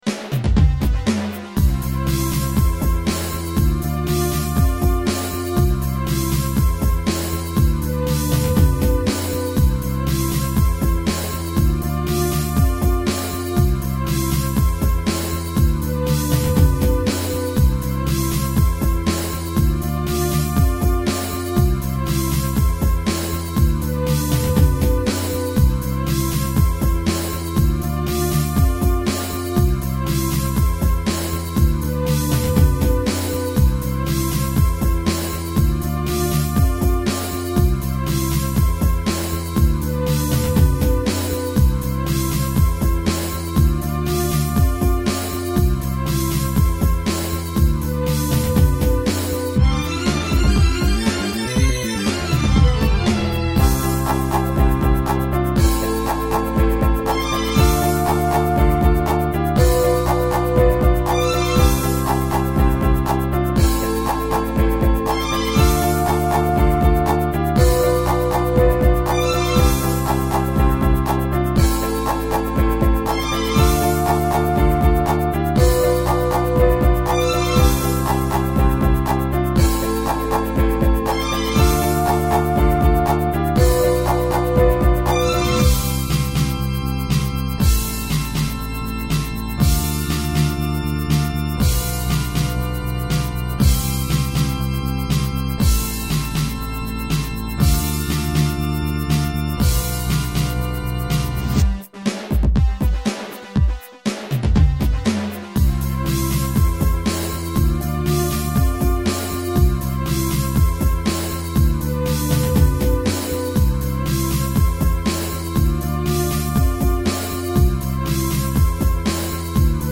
• Жанр: Танцевальная
Synh-Pop / Euro-Pop. 2001.